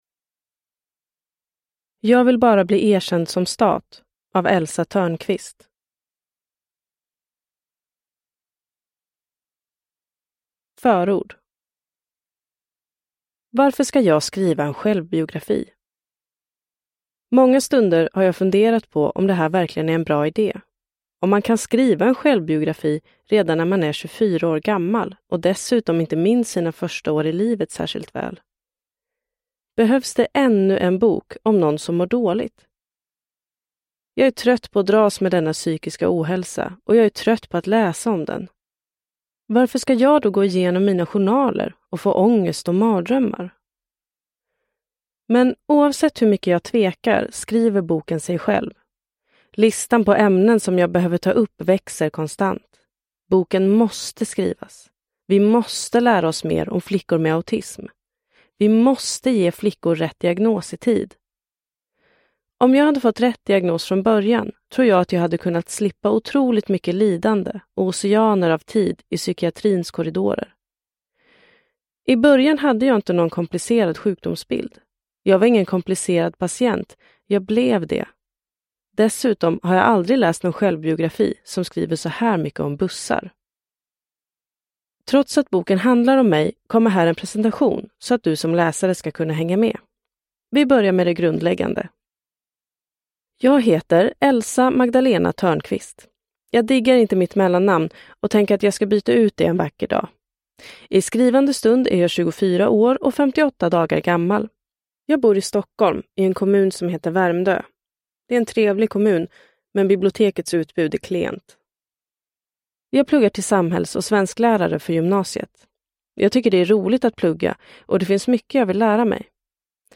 Jag vill bara bli erkänd som stat : En självbiografi om autism – Ljudbok – Laddas ner